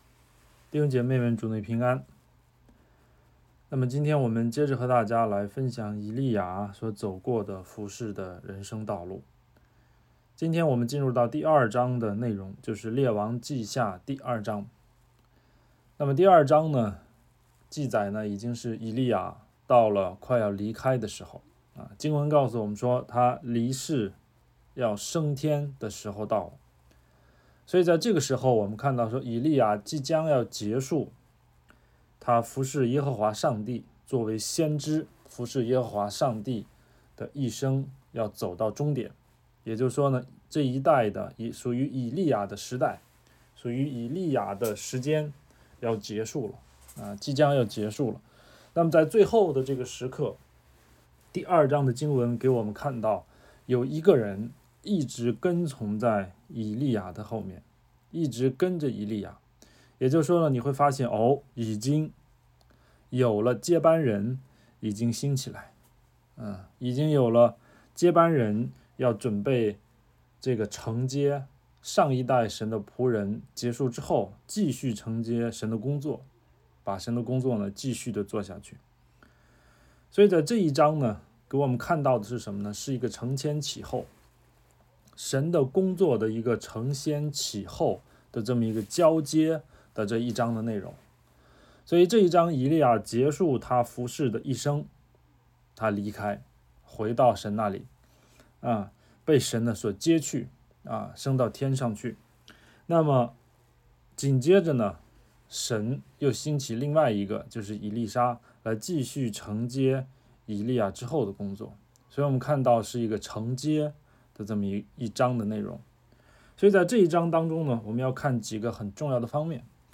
《跟从到底》 证道